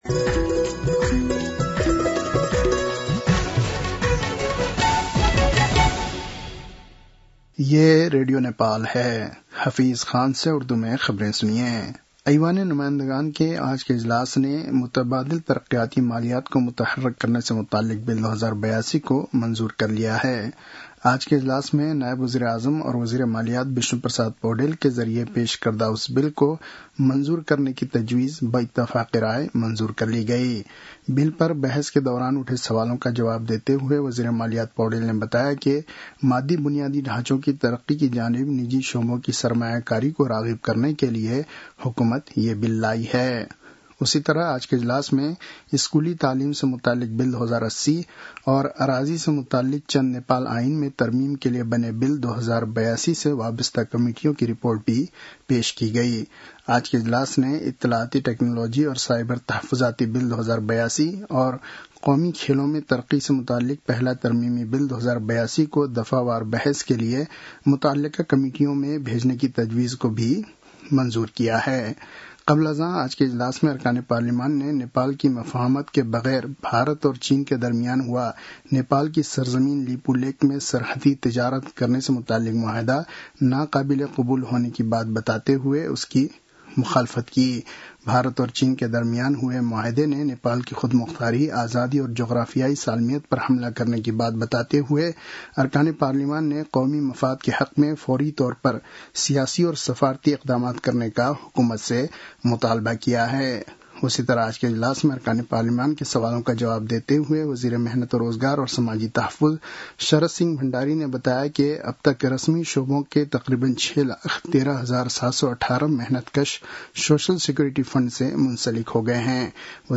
उर्दु भाषामा समाचार : ६ भदौ , २०८२